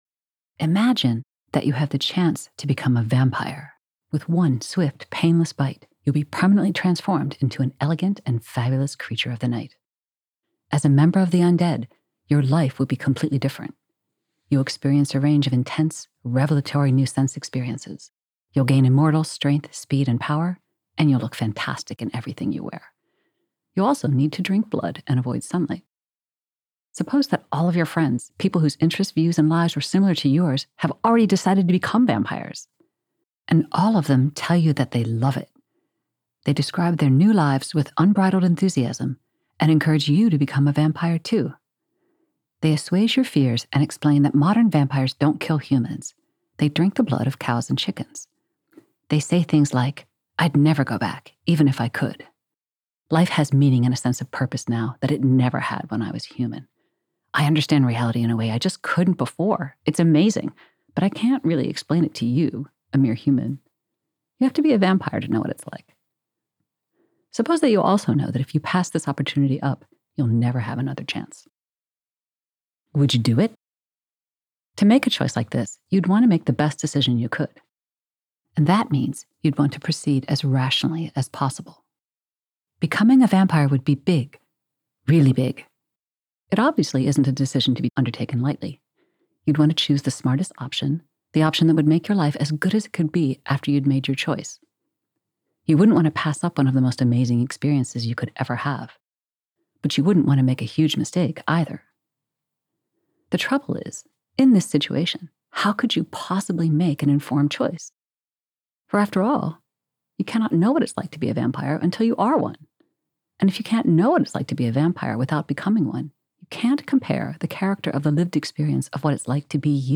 by L. A. Paul. read by the author